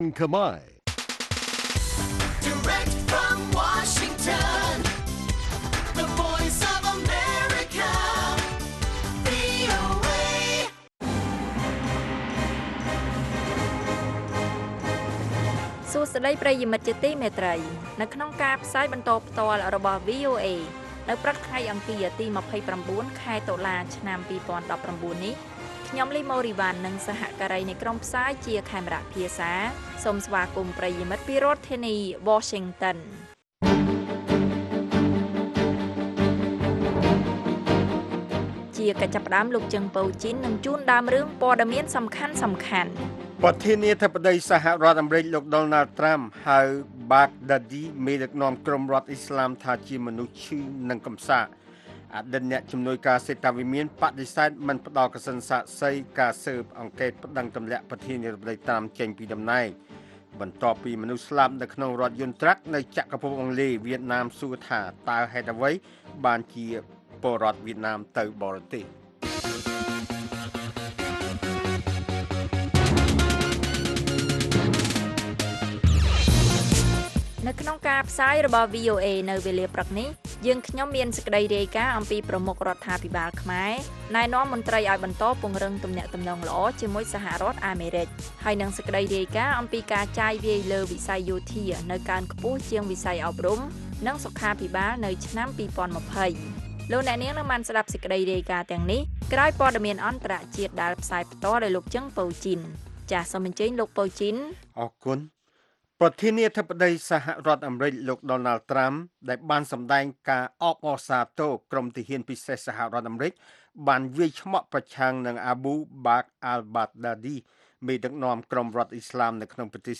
នេះជាកម្មវិធីផ្សាយប្រចាំថ្ងៃតាមវិទ្យុជាភាសាខ្មែរ រយៈពេល ៣០នាទី ដែលផ្តល់ព័ត៌មានអំពីប្រទេសកម្ពុជានិងពិភពលោក ក៏ដូចជាព័ត៌មានពិពណ៌នា ព័ត៌មានអត្ថាធិប្បាយ និងបទវិចារណកថា ជូនដល់អ្នកស្តាប់ភាសាខ្មែរនៅទូទាំងប្រទេសកម្ពុជា។